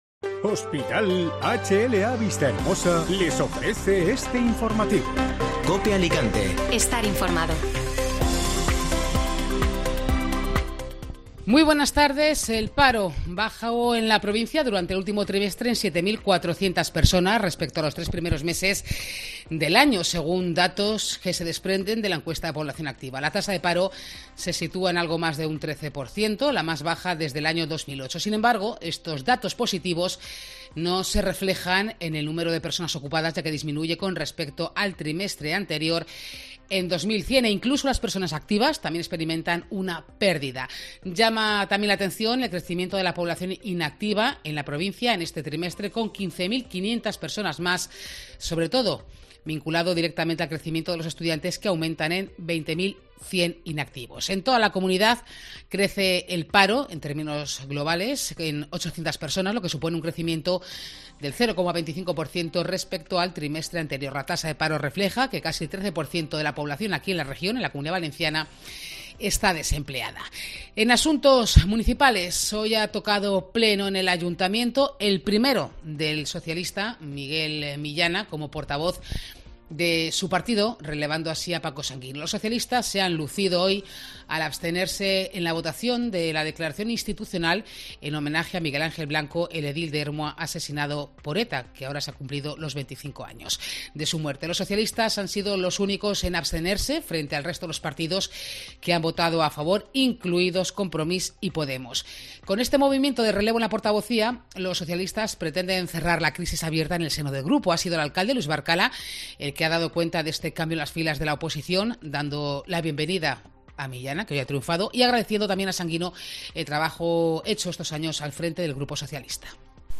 Informativo Mediodía Cope (Jueves 28 de Julio)